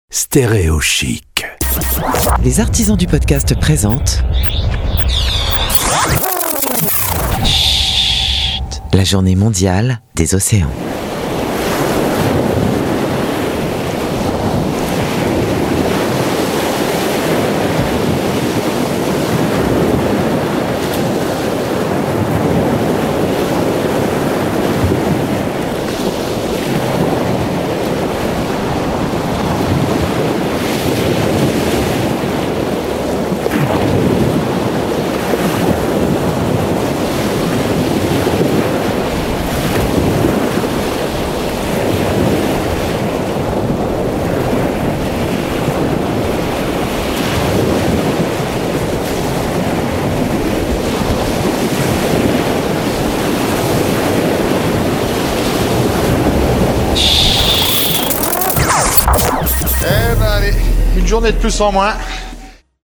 A l'occasion de la Journée Mondiale des océans, le 8 Juin 2021, voici une immersion de 60 secondes avec Schhhhhht produit par les Artisans du Podcast.